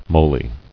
[mo·ly]